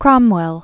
(krŏmwĕl, -wəl, krŭm-), Oliver 1599-1658.